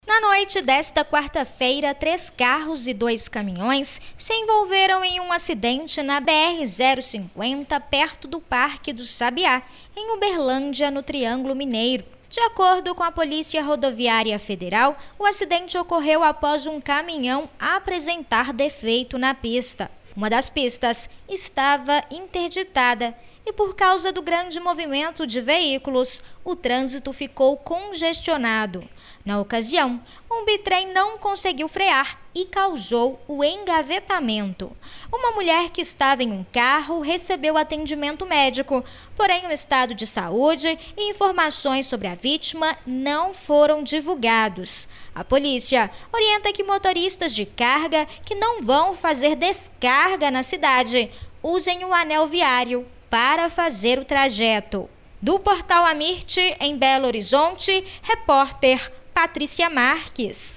AMIRT Gerais Notícias em áudio RelevanteThe estimated reading time is less than a minute